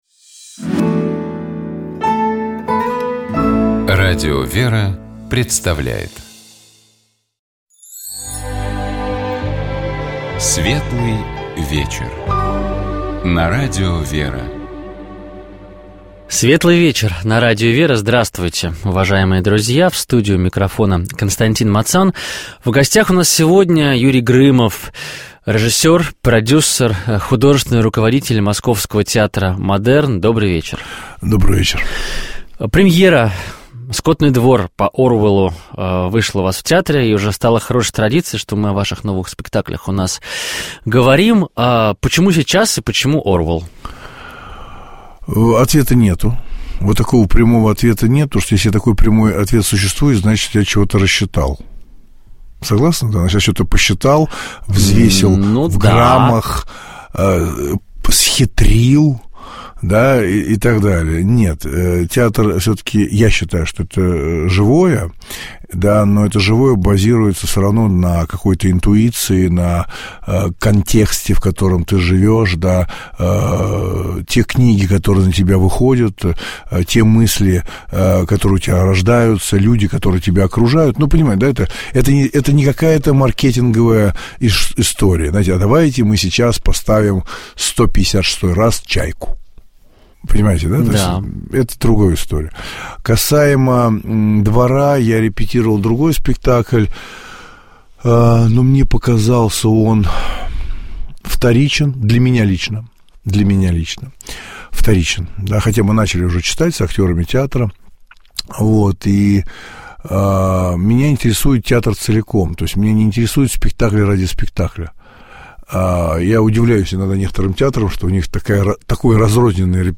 У нас в студии был режиссер, художественный руководитель театра «Модерн» Юрий Грымов.